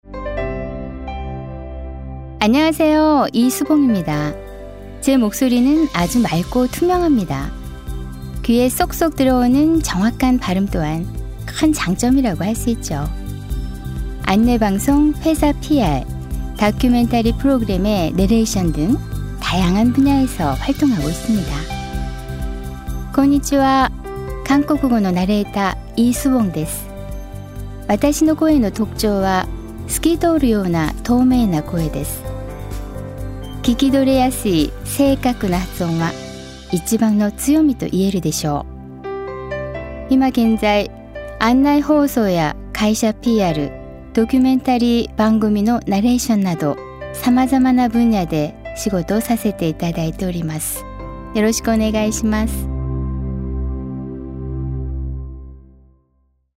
Self-introduction.mp3